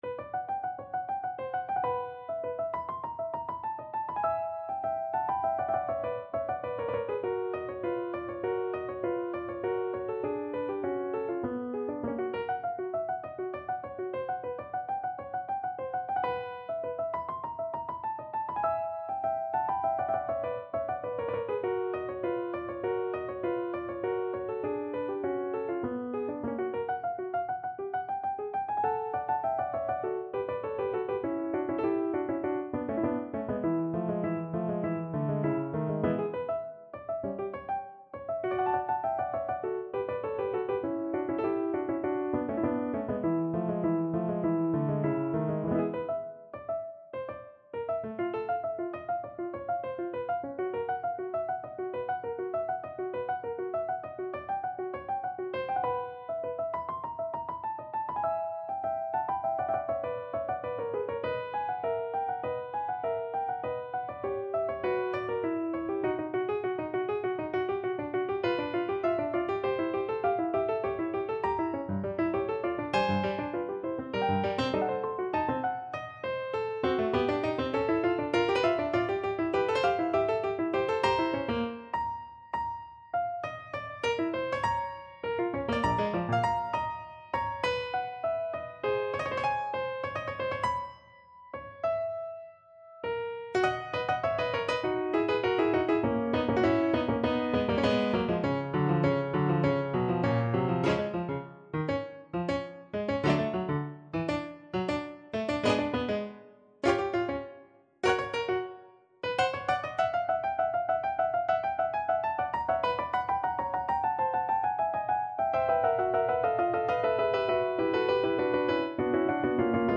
No parts available for this pieces as it is for solo piano.
2/4 (View more 2/4 Music)
Allegretto no troppo
Piano  (View more Advanced Piano Music)
Classical (View more Classical Piano Music)